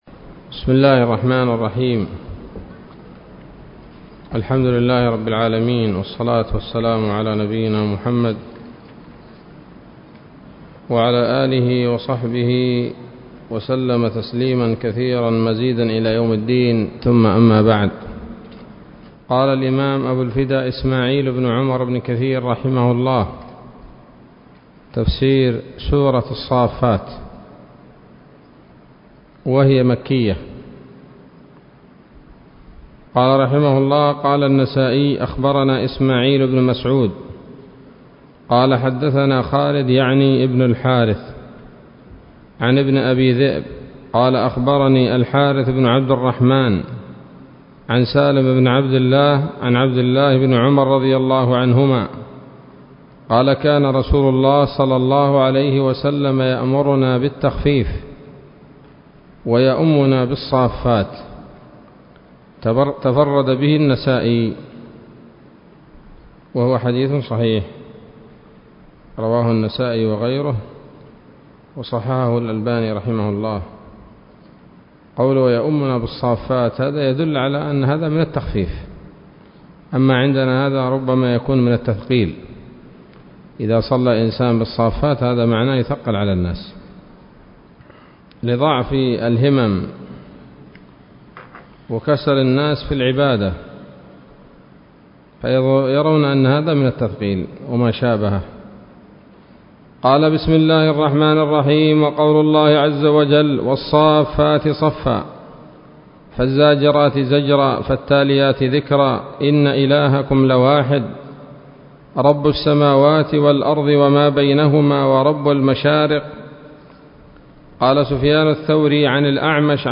الدرس الأول من سورة الصافات من تفسير ابن كثير رحمه الله تعالى